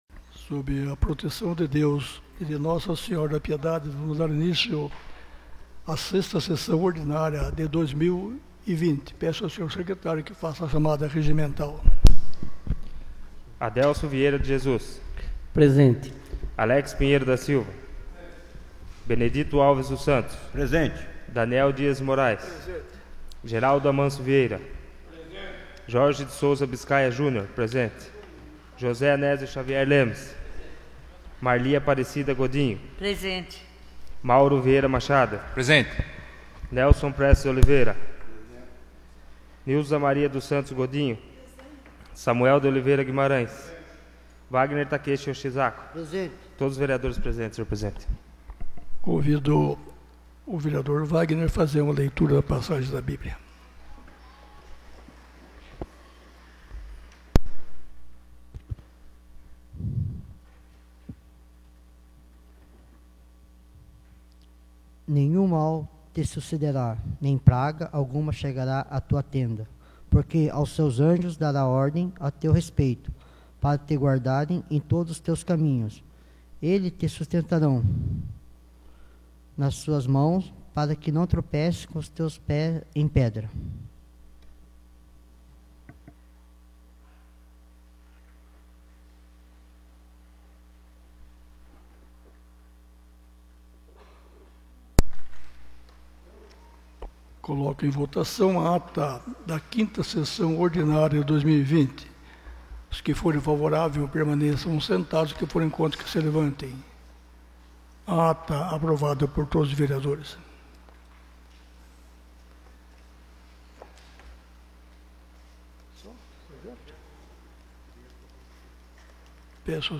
6ª Sessão Ordinária de 2020